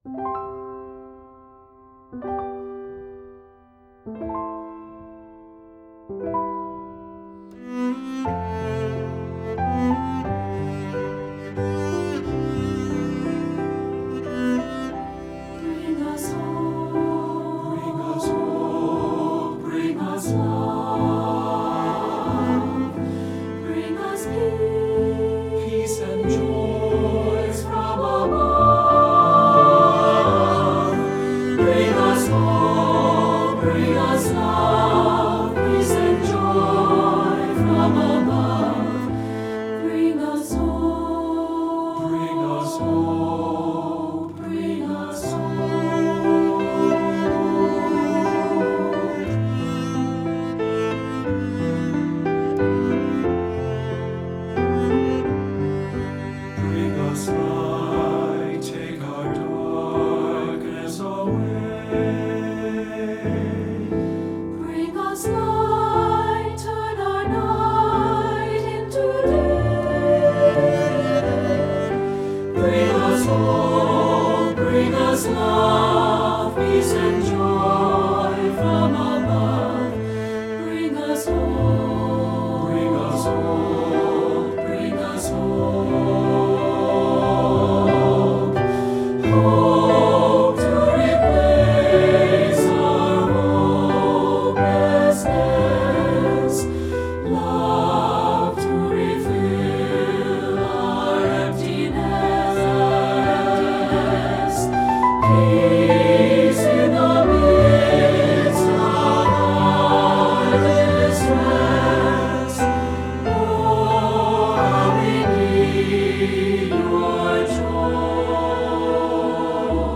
Voicing SATB/SAB